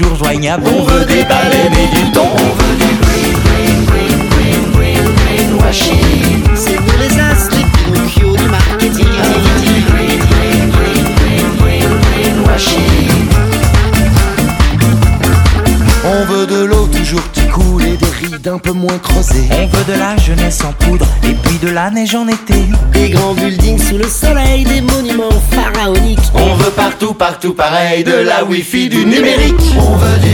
trois guitaristes et un percussionniste
Chanson française